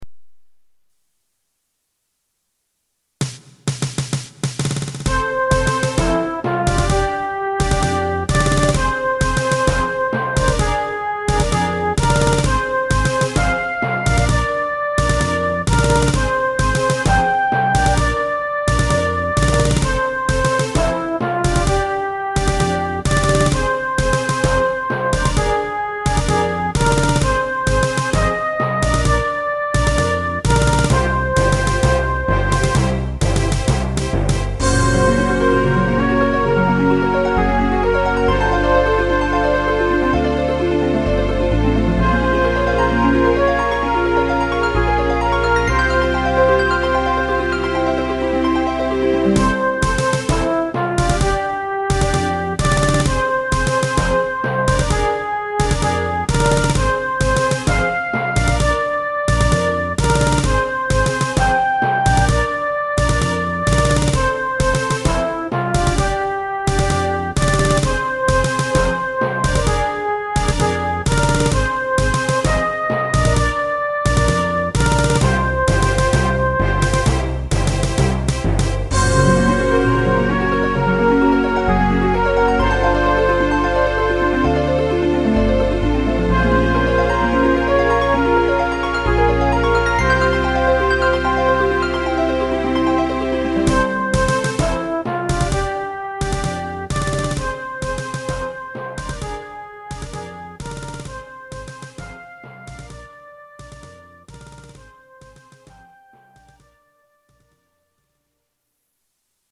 管理人が作ったMIDI集です